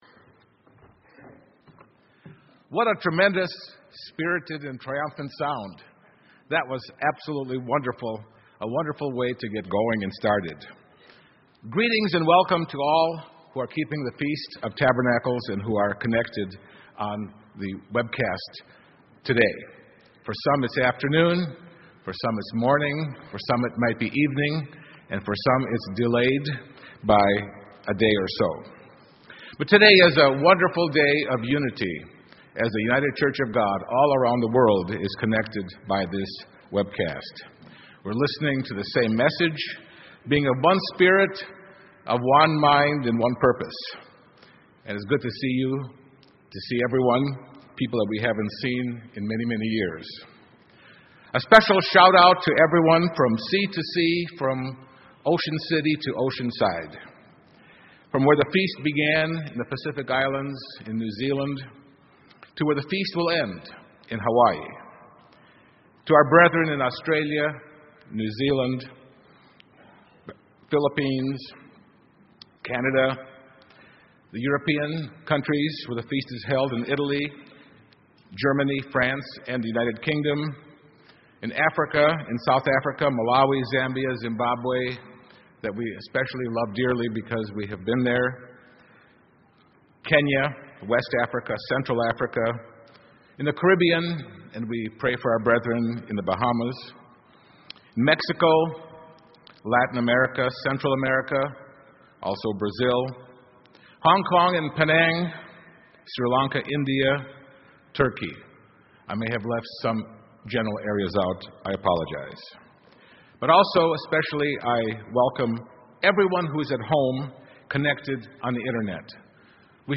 This sermon was given on the Sabbath during the 2015 Feast of Tabernacles in Sevierville, Tennessee. What will the character be of those who will live forever in the Kingdom of God?
This sermon was given at the Sevierville, Tennessee 2015 Feast site.